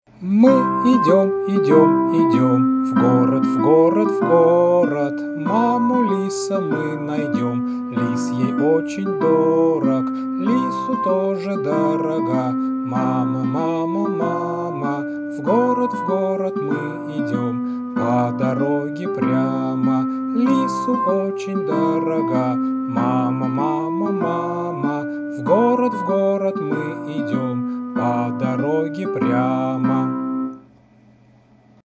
• Жанр: Детская